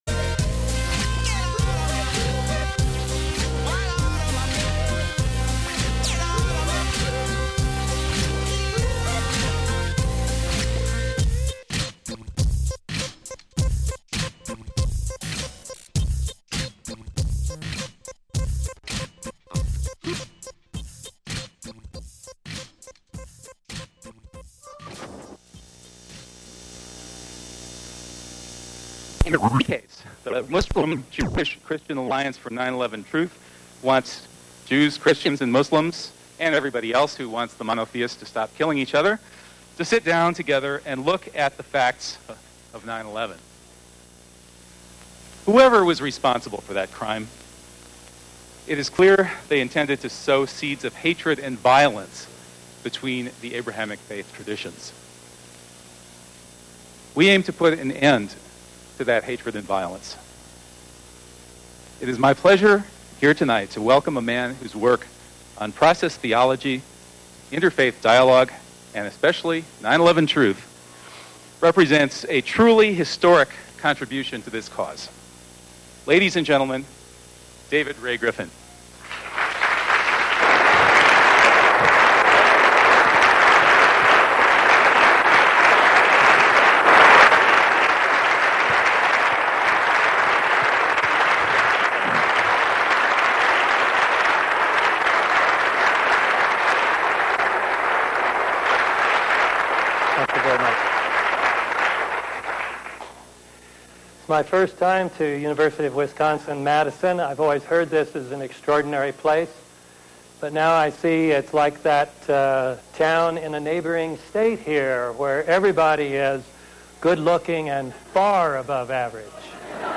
Speech on 911 Errors, Ommissions and Lies